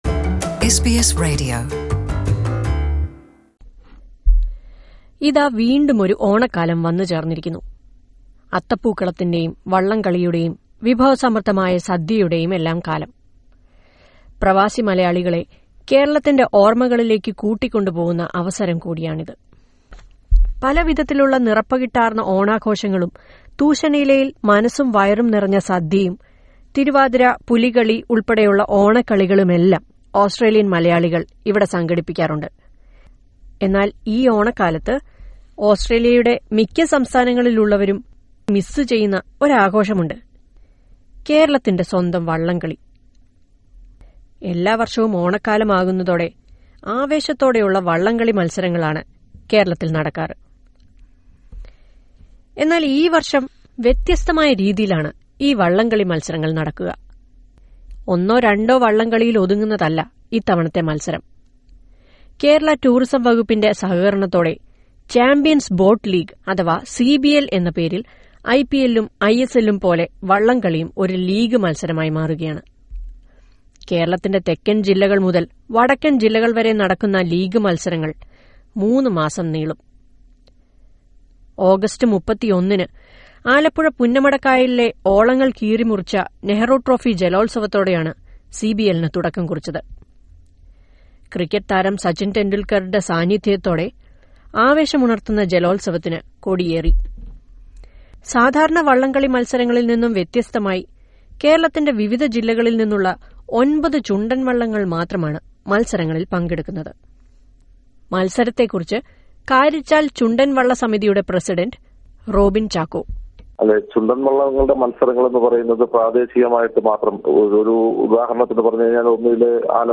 The boat race in Kerala during the time of Onam has taken a new shape with the inauguration of Champions Boat League. Listen to a report on this.